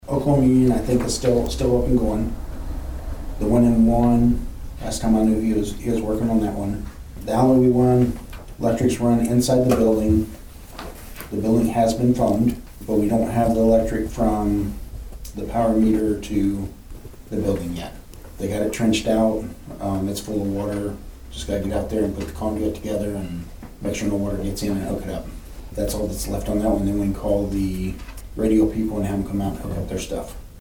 The Nowata County Commissioners met for the first time in September on Tuesday morning at the Nowata County Annex.
Chairman Paul Crupper gave an update on the recent communications tower project.